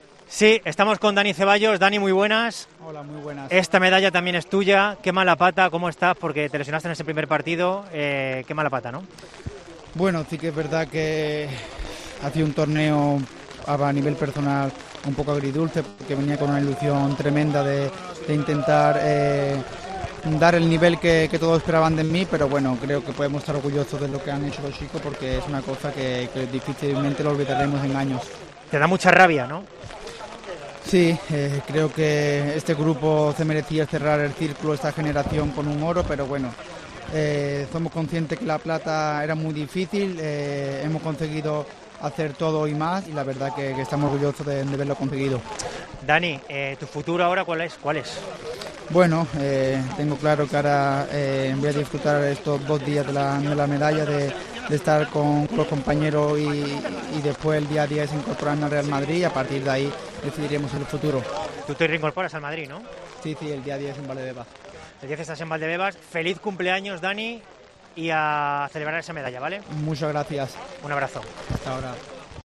El futbolista de la selección española comentó en COPE sus sensaciones tras finalizar los Juegos Olímpicos con la medalla de plata.